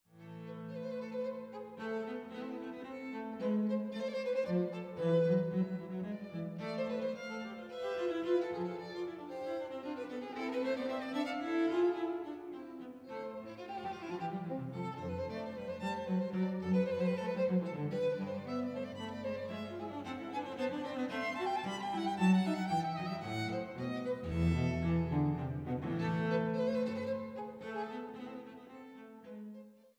Violine
Violincello